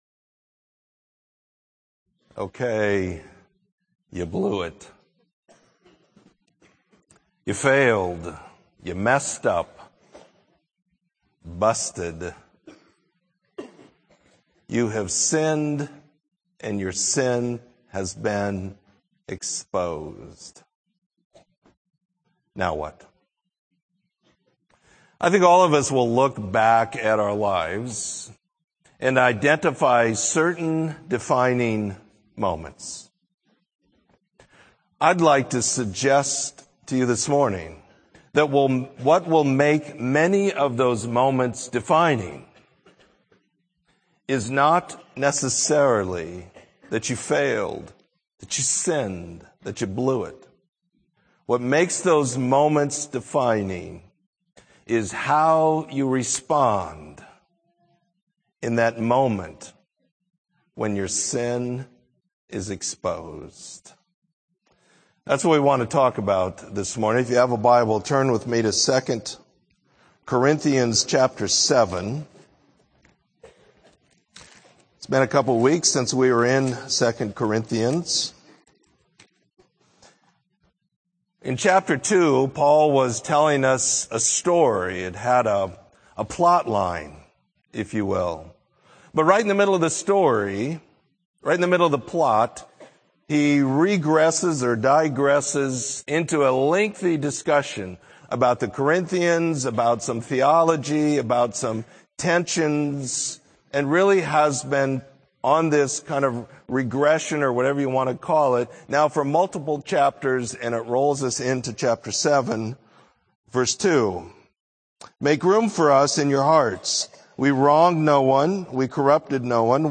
Sermon: Godly Sorrow Versus Worldly Sorrow